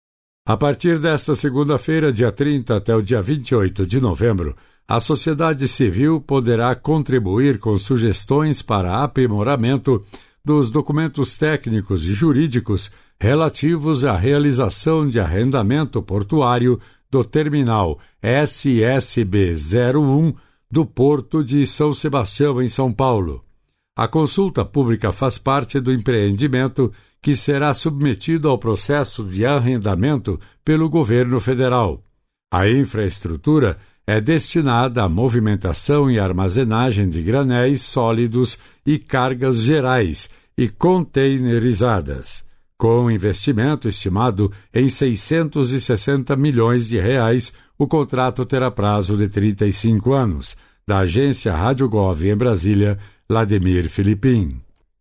É Notícia